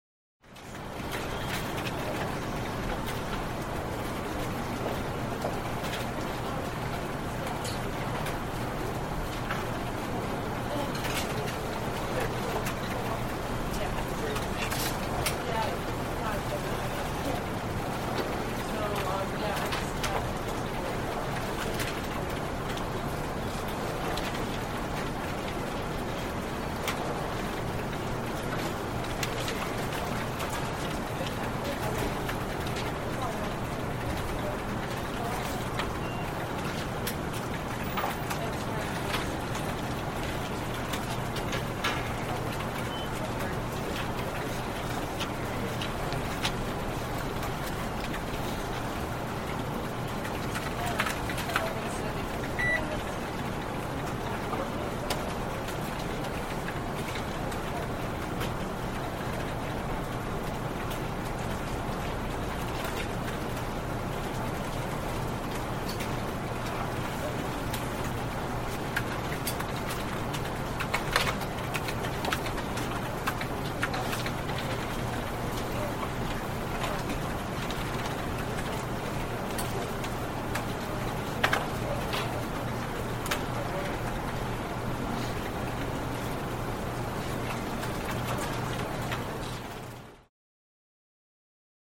Полицейский участок: голоса, телефоны, окружение Скачать звук music_note Офис , фирма save_as 3.9 Мб schedule 4:22:00 4 6 Теги: wav , атмосфера , Голоса , звук , обстановка , офис , помещения , телефон